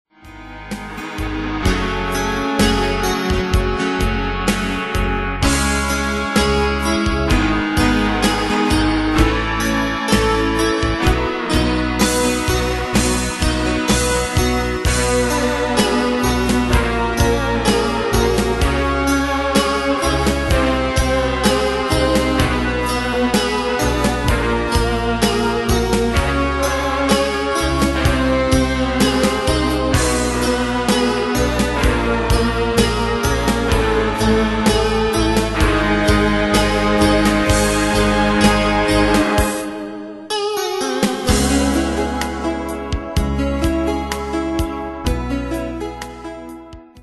Danse/Dance: Soft Cat Id.
Pro Backing Tracks